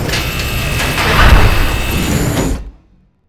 CloseAirlock-1.wav